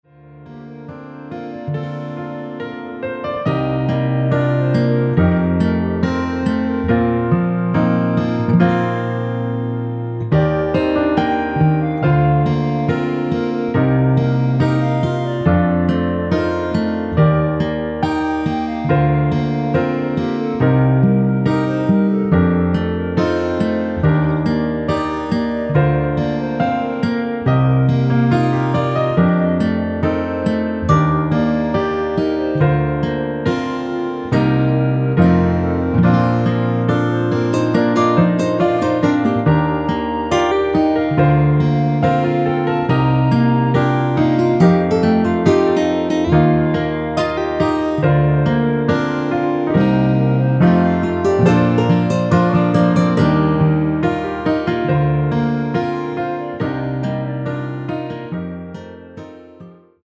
멜로디 포함된1절후 후렴으로 진행되게 편곡 하였습니다.(아래의 가사 참조)
Bb
멜로디 MR이라고 합니다.
앞부분30초, 뒷부분30초씩 편집해서 올려 드리고 있습니다.
중간에 음이 끈어지고 다시 나오는 이유는